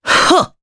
Pavel-Vox_Attack4_jp_b.wav